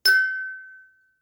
New_Email.mp3